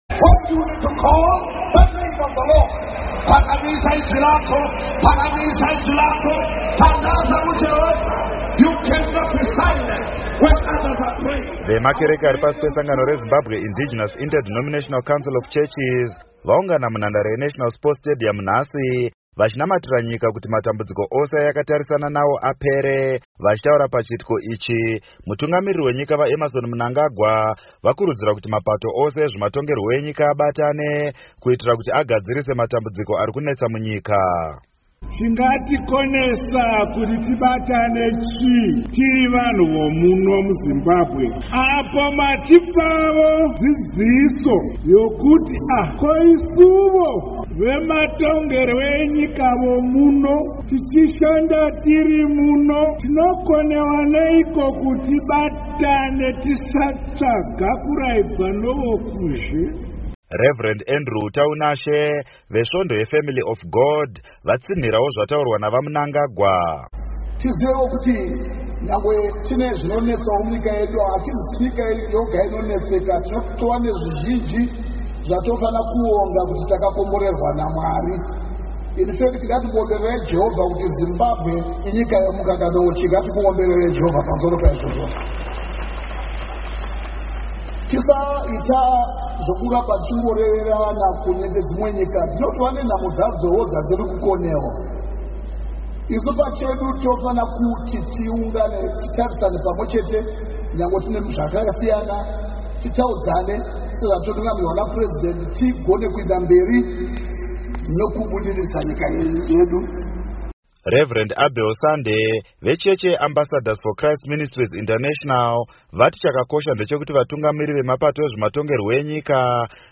Churches - Prayer meeting